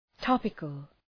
Προφορά
{‘tɒpıkəl}